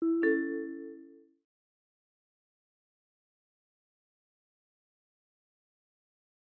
Звук появления объекта или рисунка на экране